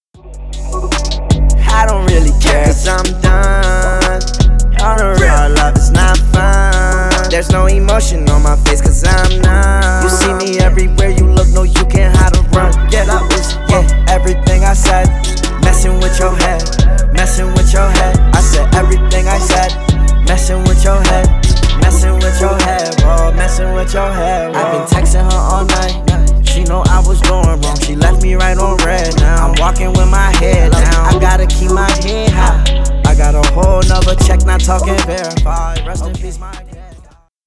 • Качество: 320 kbps, Stereo
Рэп и Хип Хоп